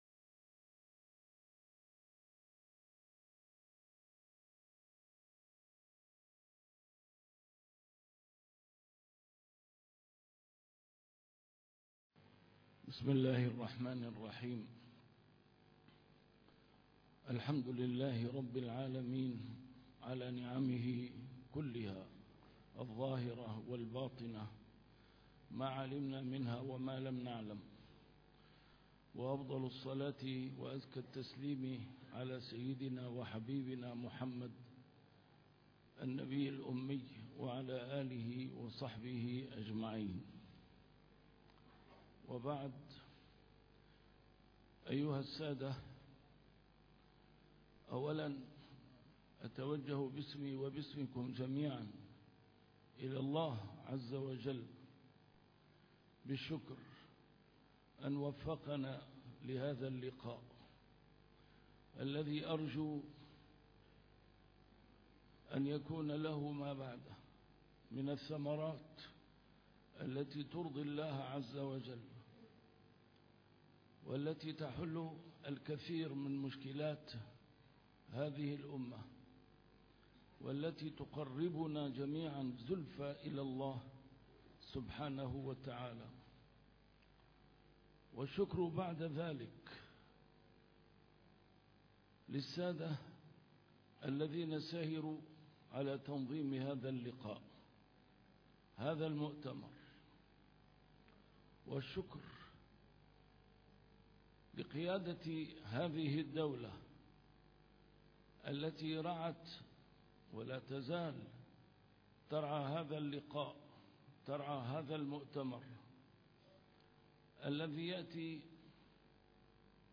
كلمة الجلسة الافتتاحية في مؤتمر حضرموت